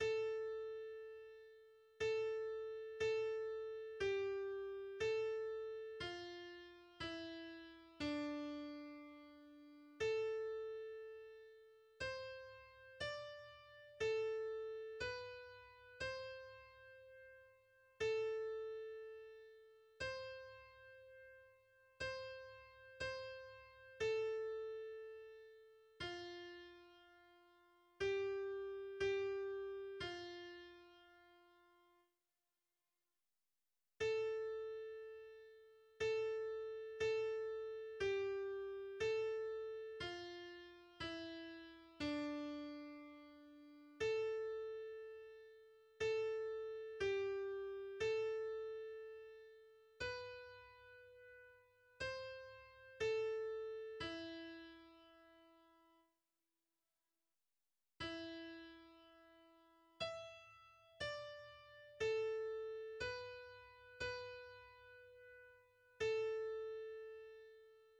Text & Melodi Volkslied